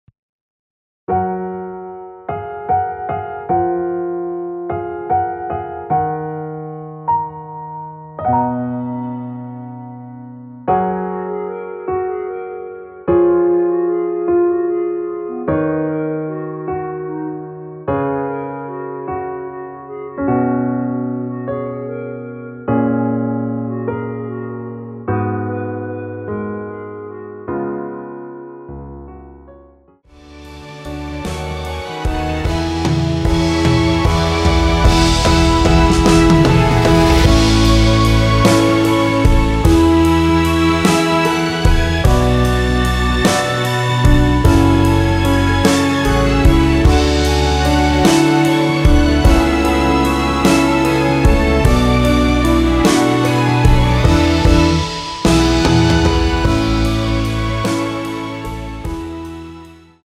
원키에서(+1)올린 멜로디 포함된 MR입니다.(미리듣기 확인)
F#
앞부분30초, 뒷부분30초씩 편집해서 올려 드리고 있습니다.